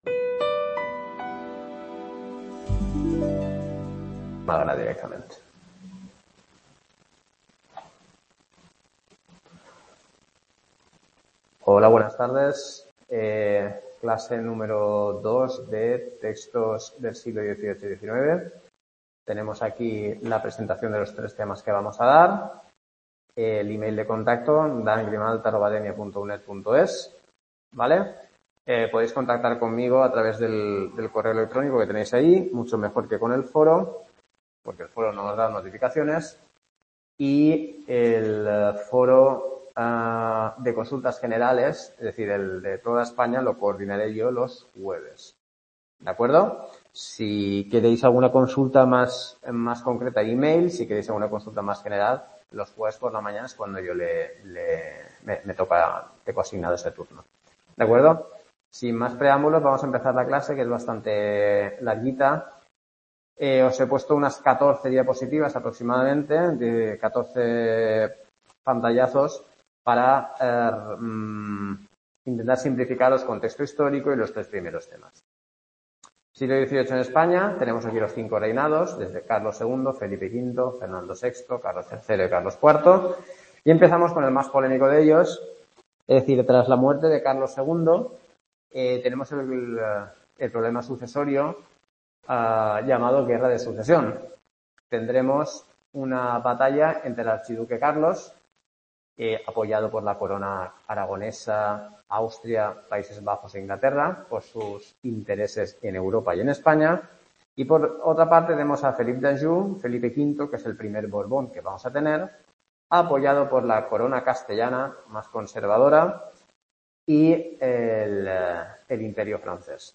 CLASE 2 TEXTOS XVIII Y XIX | Repositorio Digital